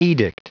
Prononciation du mot edict en anglais (fichier audio)
Prononciation du mot : edict